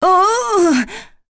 Seria-Vox_Damage_03.wav